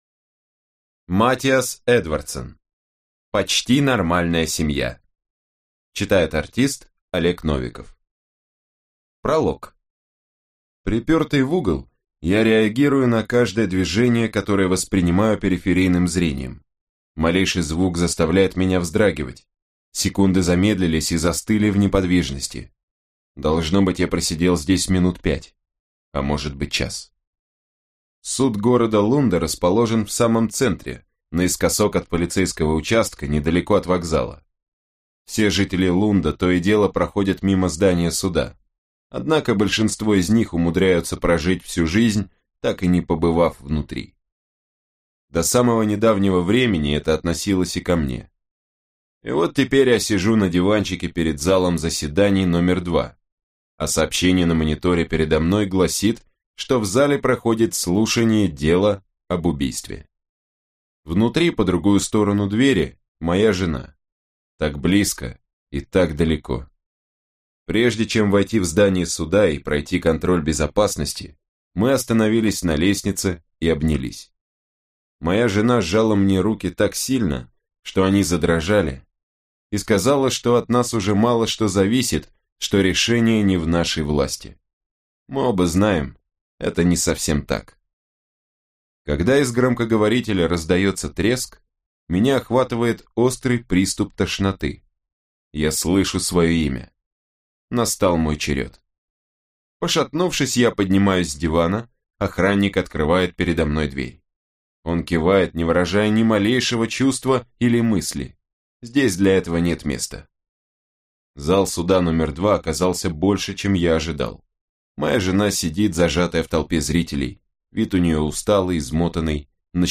Аудиокнига Почти нормальная семья | Библиотека аудиокниг
Прослушать и бесплатно скачать фрагмент аудиокниги